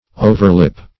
Search Result for " overlip" : Wordnet 3.0 NOUN (1) 1. the upper lip ; The Collaborative International Dictionary of English v.0.48: Overlip \O"ver*lip`\, n. [AS. oferlibban.]